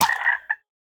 minecraft / sounds / mob / frog / death2.ogg